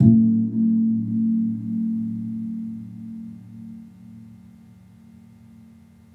Gamelan Sound Bank
Gong-A1-f.wav